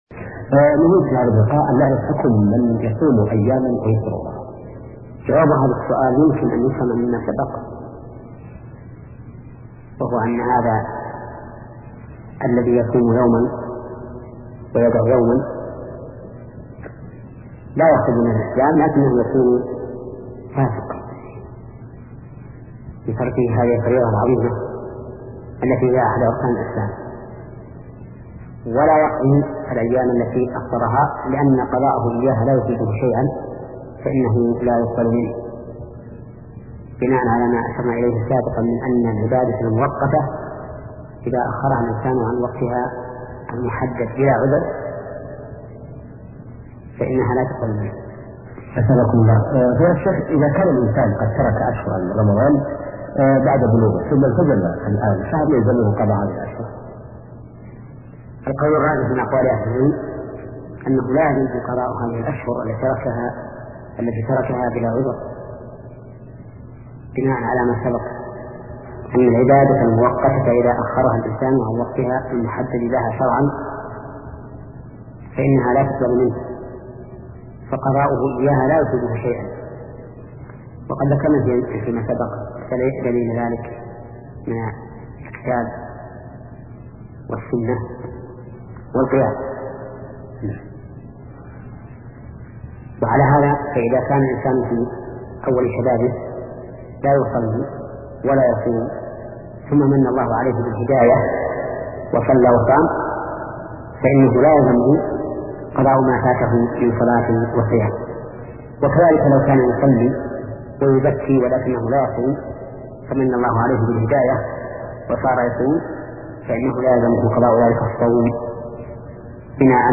فقه العبادات [63] - للشيخ : ( محمد بن صالح العثيمين ) صيام رمضان فرض على المكلفين لا يسقط بحال، ومن تركه أتى باباً عظيماً من الكبائر، كذلك يجب المحافظة عليه مما يفسده ويخدشه، كالجماع والغيبة والظلم.